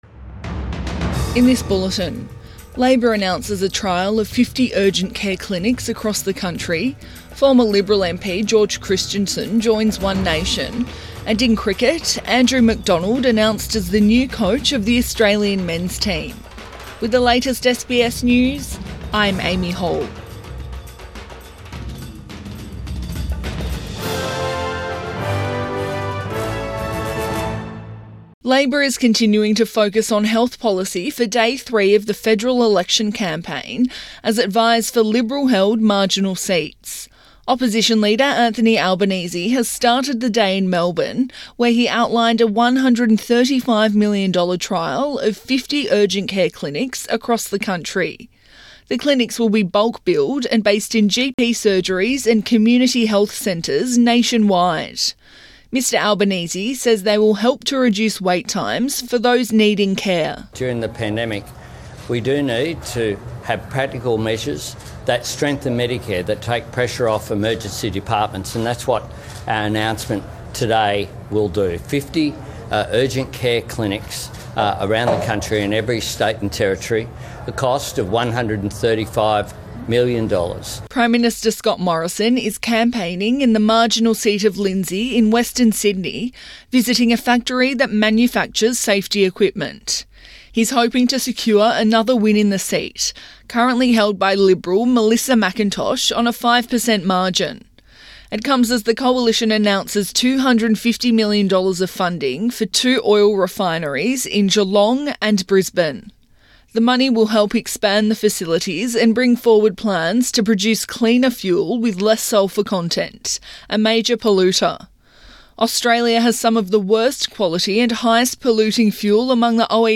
Midday bulletin 13 April 2022